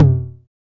• Mid TR 909 Synth Tom C Key 51.wav
Royality free tr 909 synth tom sample tuned to the C note. Loudest frequency: 215Hz